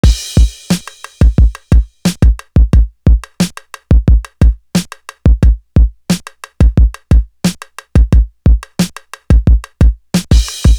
Living The Life Drum.wav